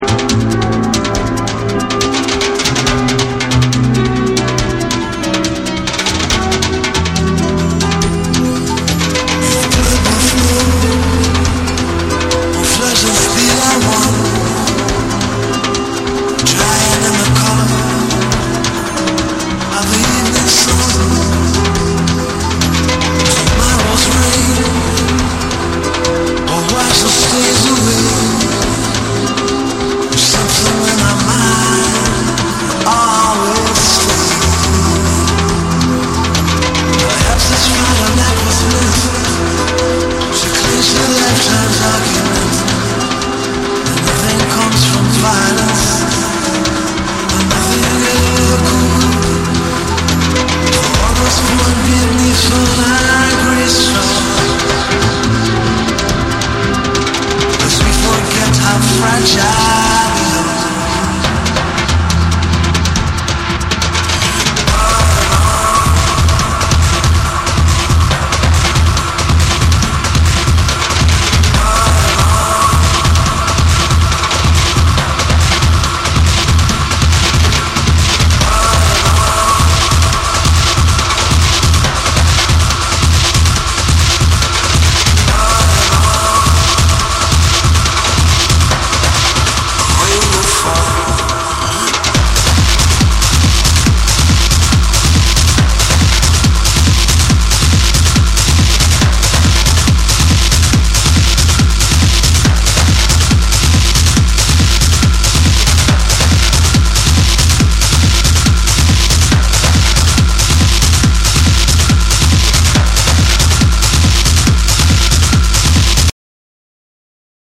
TECHNO & HOUSE / NEW WAVE & ROCK